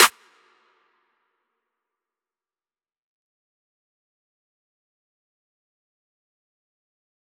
Claps
DMV3_Clap 16.wav